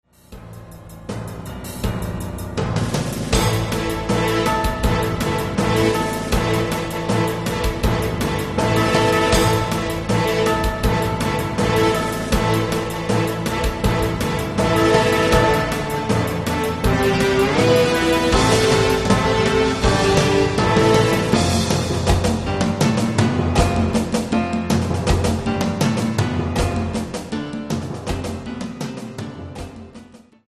演劇、人形劇、舞踏など主に舞台作品のために作曲された音楽集。